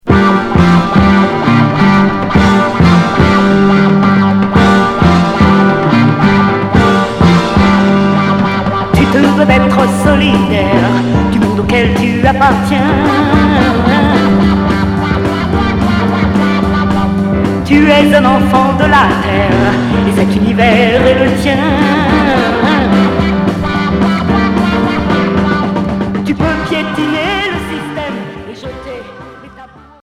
Pop psyché